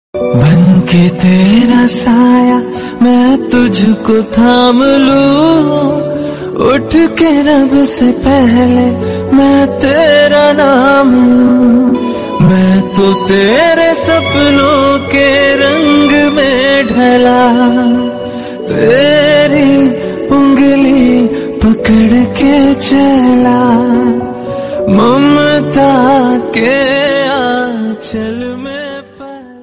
Ringtones Category: Hindi Ringtone Download Mp3 2024